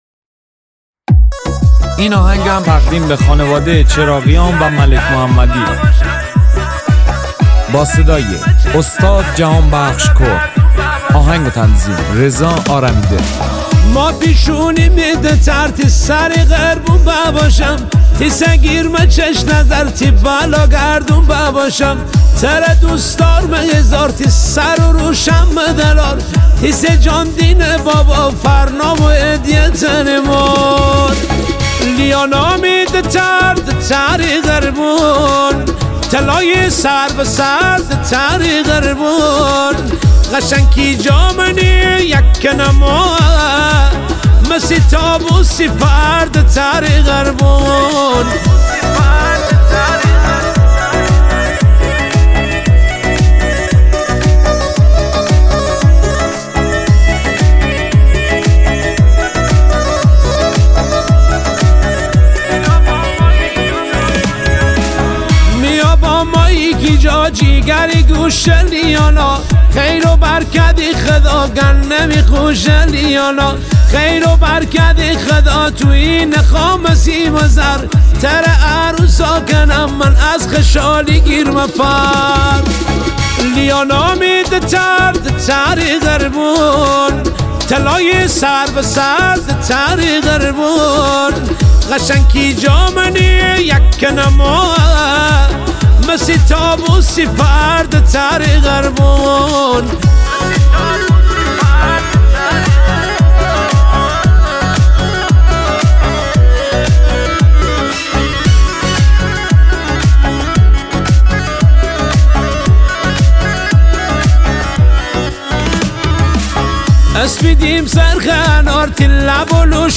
شاد
با سبک شاد مازندرانی